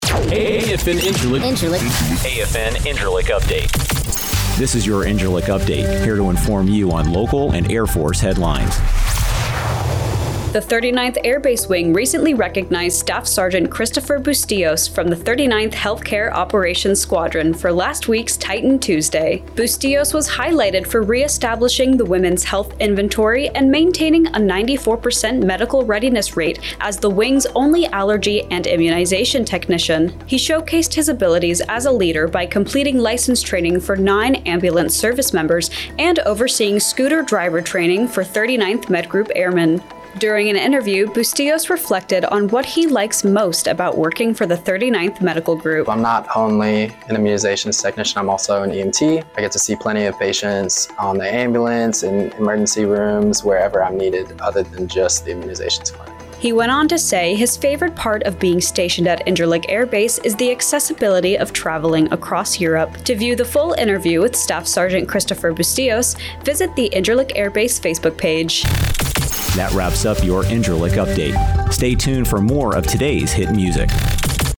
AFN INCIRLIK RADIO NEWSCAST: Titan Tuesday